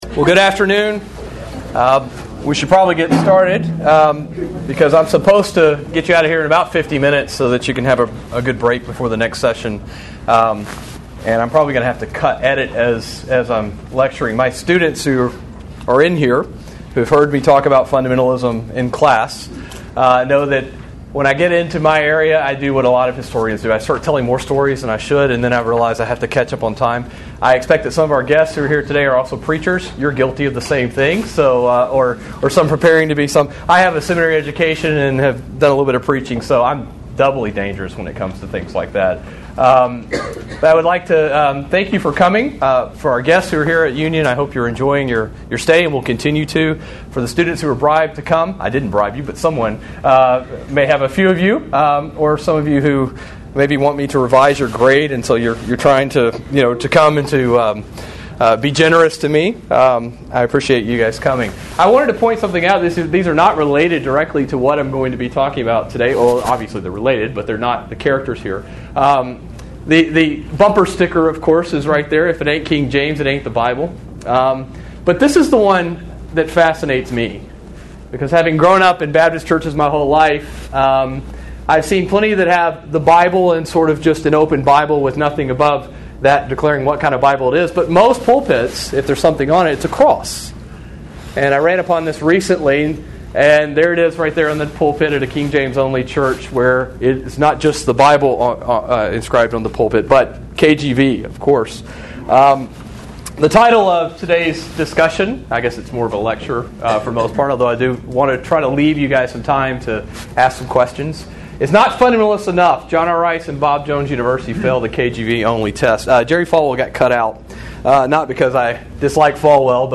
KJV400 Festival
Address: Not Fundamentalist Enough: John R. Rice, Bob Jones, Sr., and Jerry Falwell Fail the King James Only Test Recording Date